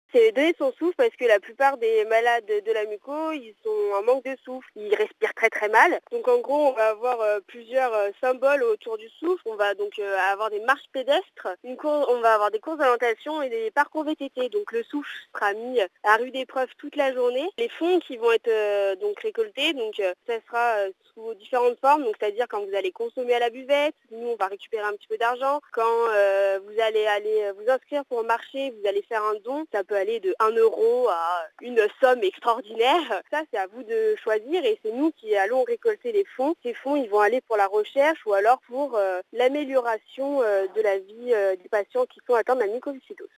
La jeune femme explique pourquoi on parle de « donner son souffle ».